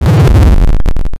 dooropen.m4a